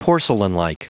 Prononciation du mot porcelainlike en anglais (fichier audio)
porcelainlike.wav